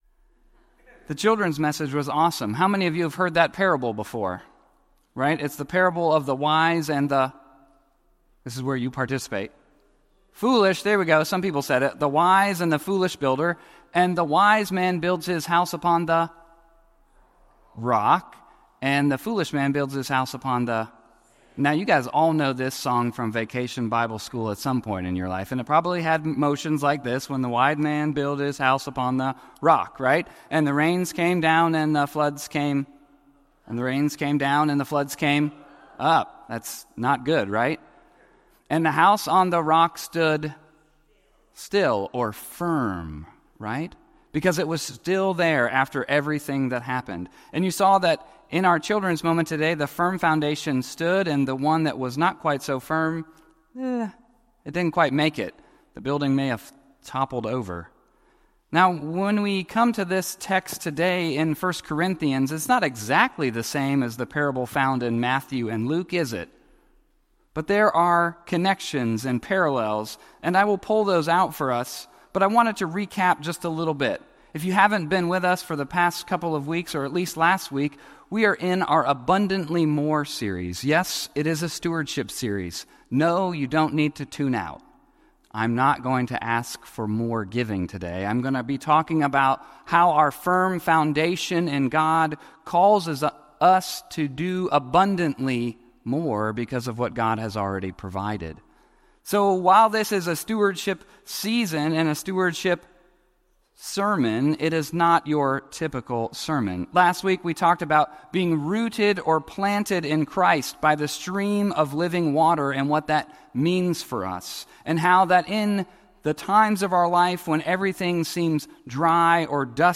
Grounded - First Houston United Methodist Church